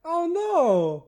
Add voiced sfx
ohno1.ogg